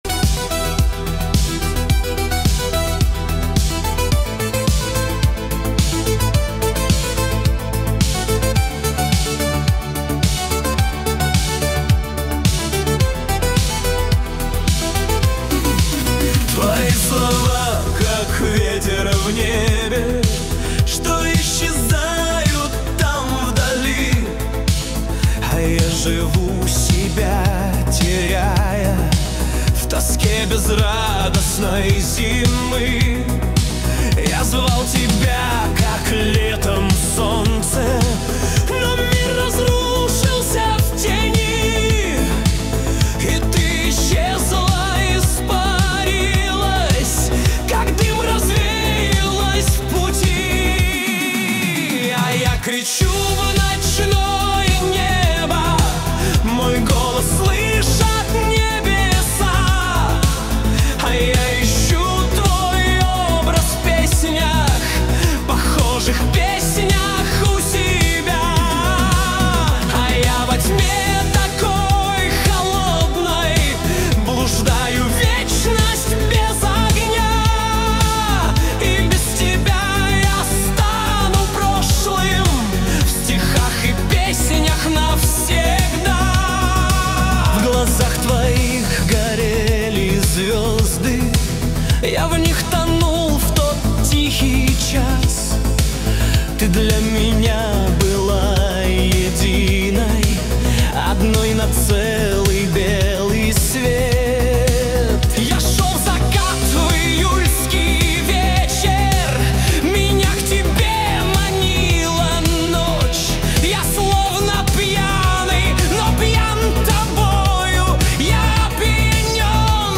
Качество: 320 kbps, stereo